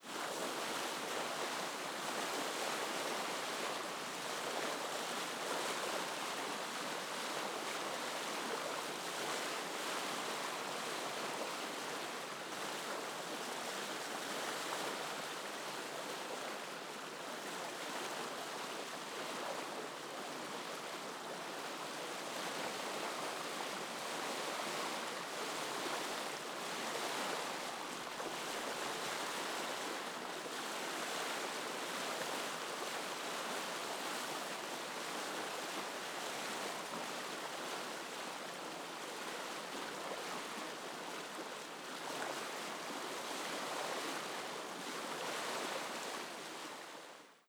sea.wav